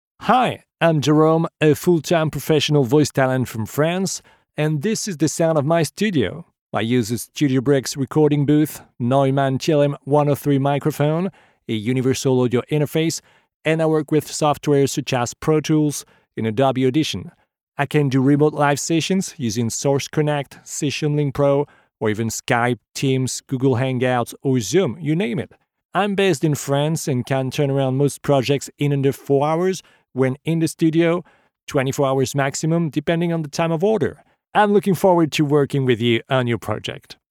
Male
My voice is warm, friendly and approachable, making it great for any story telling, with a natural and personal touch.
Studio Quality Sample
The Sound Of My Studio
Words that describe my voice are friendly, warm, natural.
All our voice actors have professional broadcast quality recording studios.